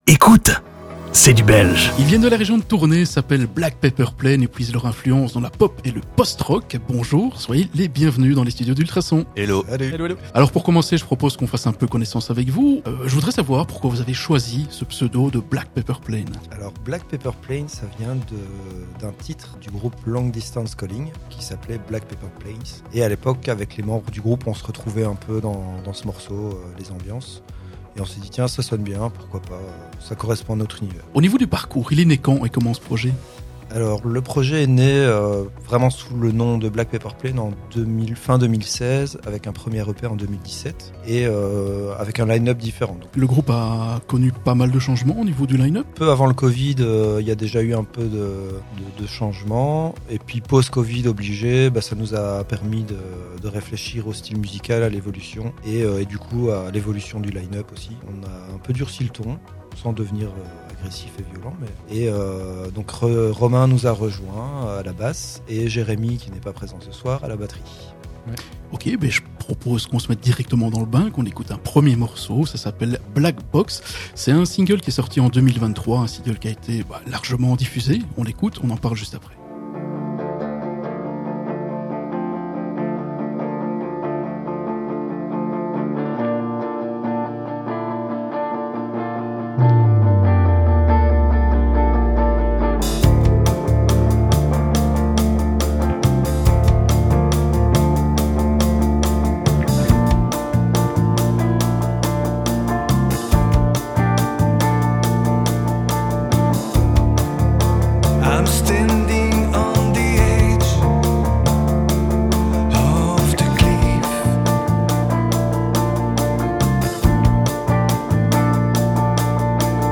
Des influences pop, post-rock et stoner.